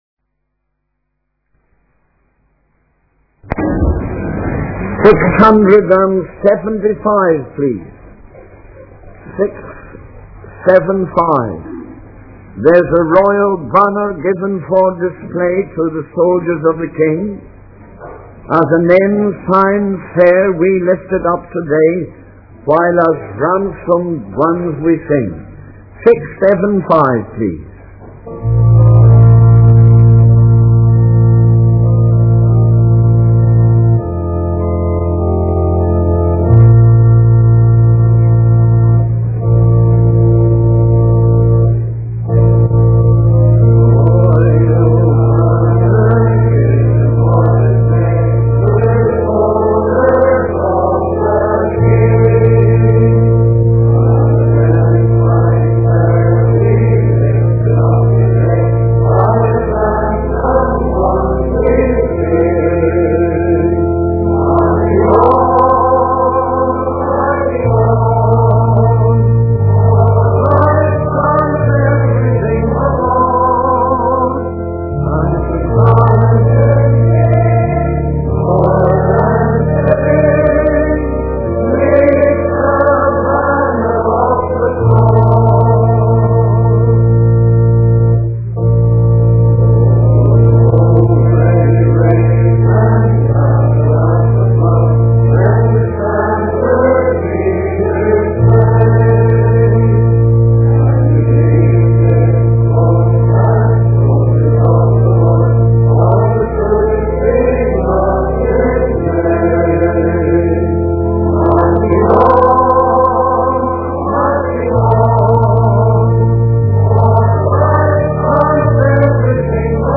In this sermon, the preacher discusses the story of Nebuchadnezzar and the three men who were thrown into the fiery furnace. He emphasizes the astonishment of the king when he sees not three, but four men walking in the fire, with the fourth being described as 'like the son of God.'